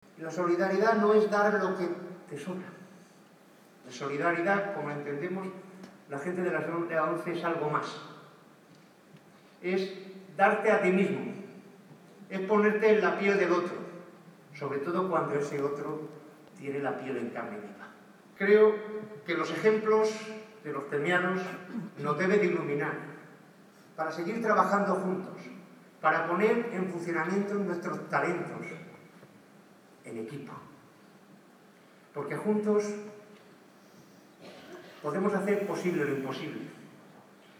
Apenas una semana después, el martes 8 de noviembre, era el Teatro Circo de Murcia el que servía de escenario a la gala de entrega de los Premios Solidarios ONCE-Murcia, 2016.